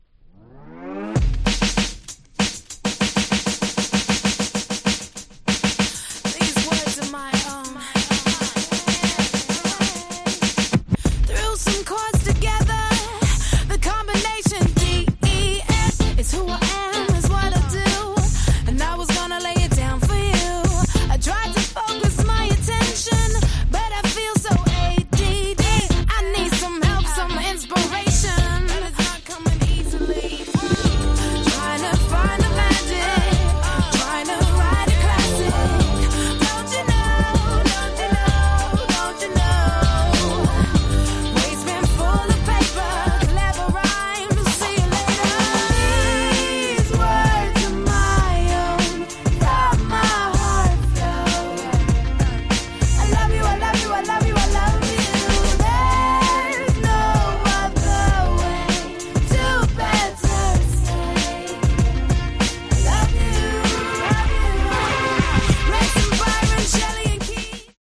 Bridgeのビートを用いた
Playするとフロアが何だか温かい雰囲気になるって言うか、すごく重宝しました！